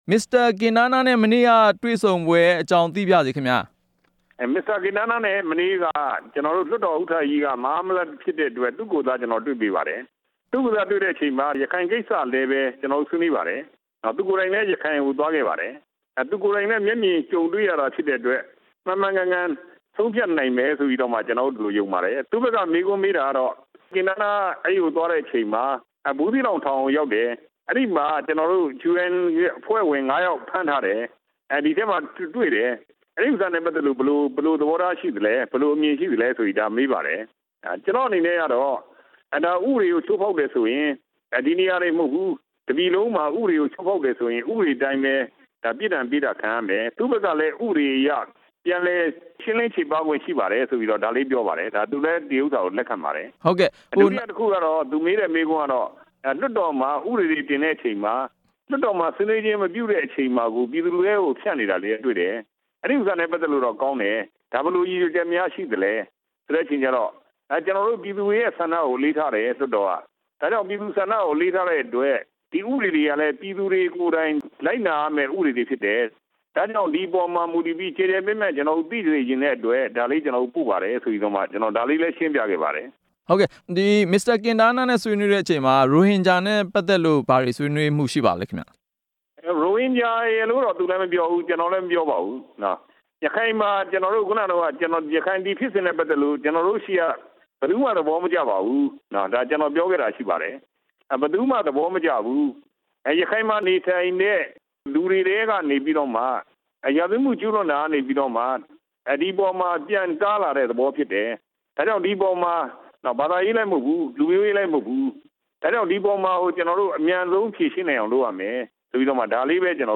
ကင်တားနားနဲ့ ကြံ့ခိုင်ဖွံ့ဖြိုးရေးပါတီ အတွင်းရေးမှူး ဦးဌေးဦး သီးသန့်ဆွေးနွေး